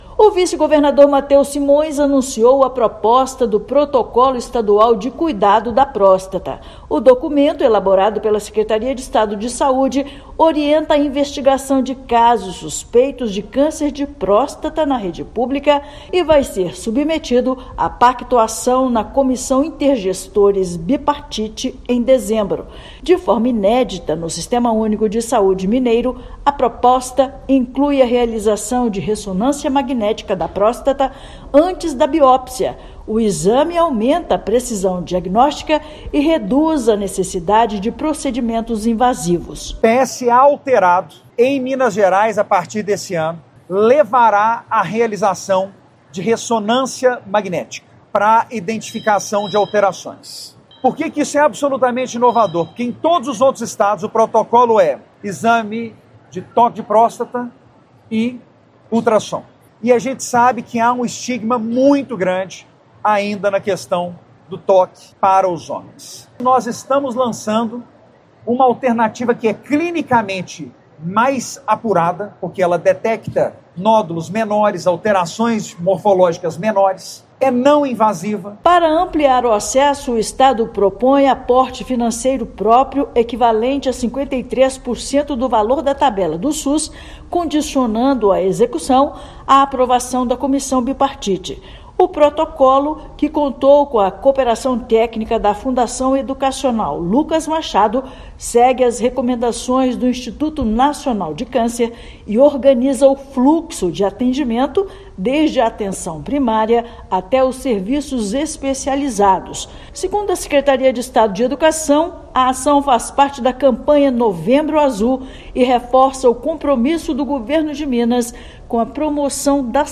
Iniciativa pioneira no país amplia acesso a exames diagnósticos, incorpora ressonância multiparamétrica e fortalece a rede oncológica estadual. Ouça matéria de rádio.